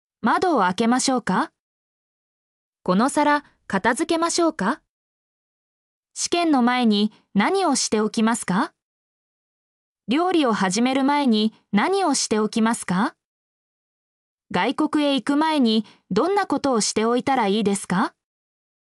mp3-output-ttsfreedotcom-18_lgTZTh5q.mp3